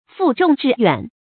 注音：ㄈㄨˋ ㄓㄨㄙˋ ㄓㄧˋ ㄧㄨㄢˇ
負重致遠的讀法